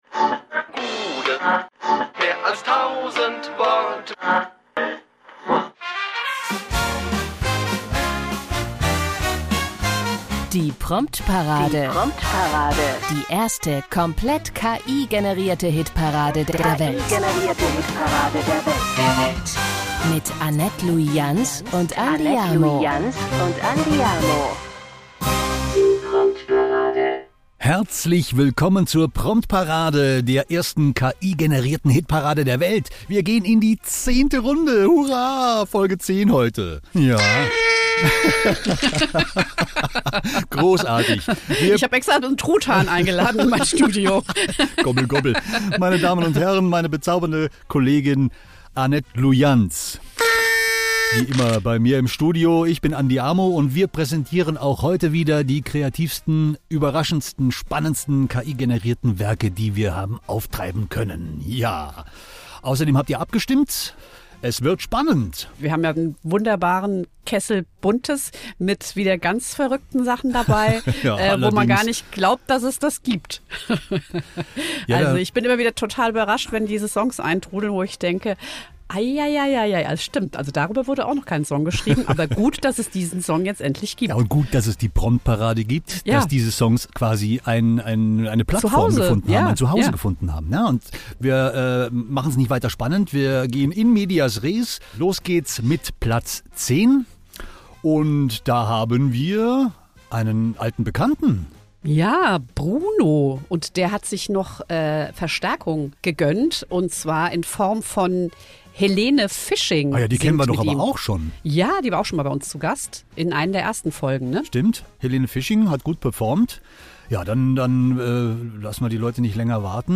Alle Songs wurden zumindest komplett von einer künstlichen Intelligenz umgesetzt.
Es wurde weder etwas eingespielt, noch eingesungen.